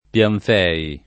[ p L anf $ i ]